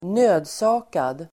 nödsakad adjektiv (formellt), compelled [formal] Uttal: [²n'ö:dsa:kad] Böjningar: nödsakat, nödsakade Definition: tvungen Exempel: han (an)såg sig nödsakad att lämna tjänsten (he felt compelled to leave his position)